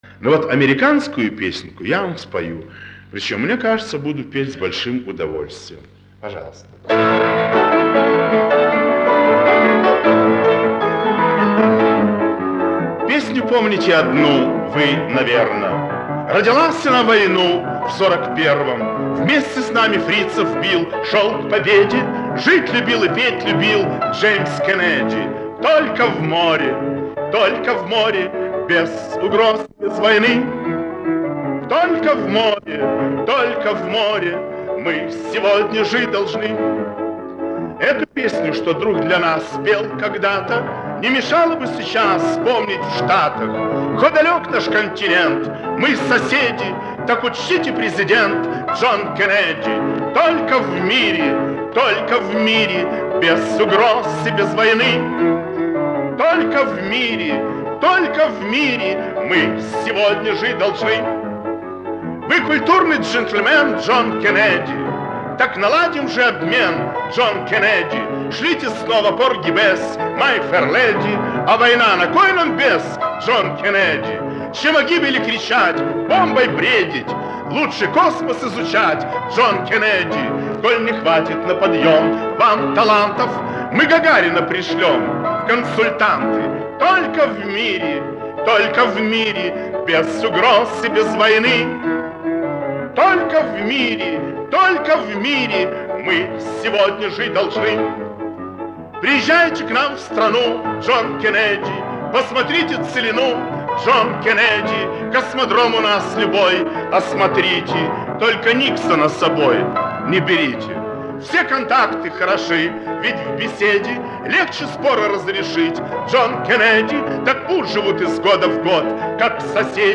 Из старых катушек отца...